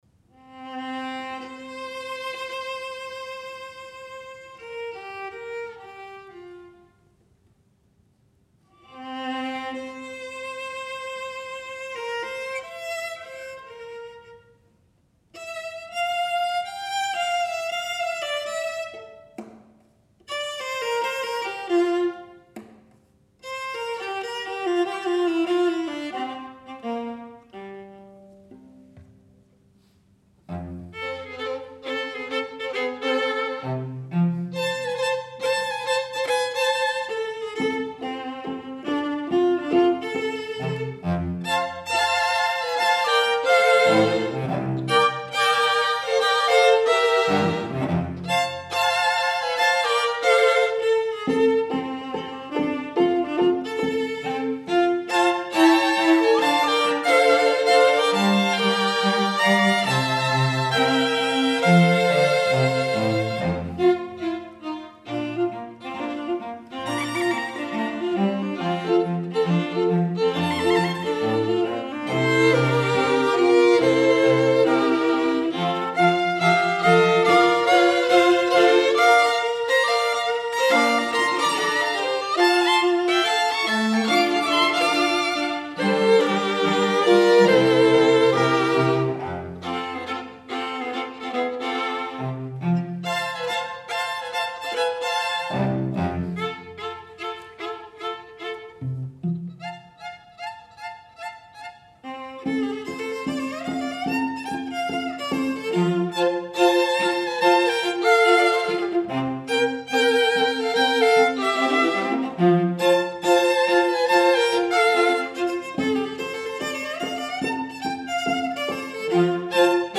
for String Quartet (2014)
The descending lines are emphatic!
Swing rhythm is featured.